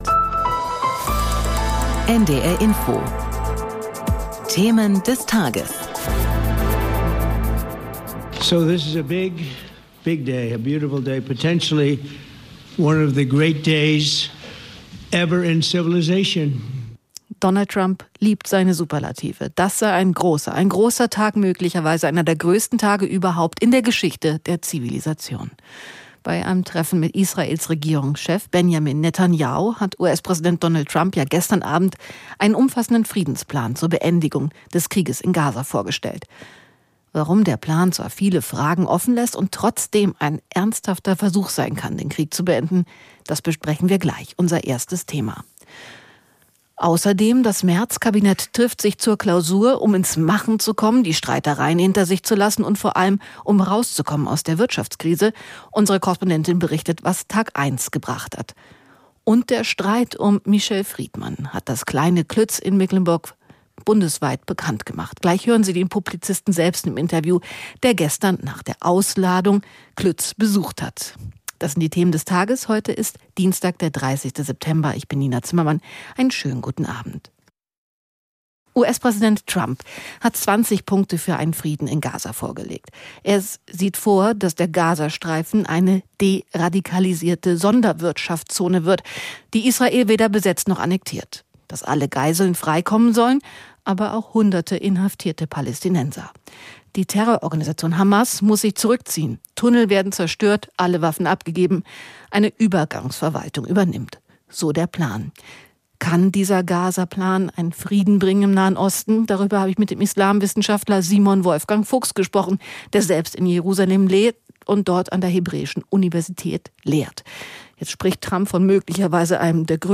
US-Präsident Trump hat seinen Nahost-Friedensplan präsentiert, Israel stimmt zu, aber noch ist offen, ob die Hamas auch mitmacht. Und: Michel Friedman im Interview.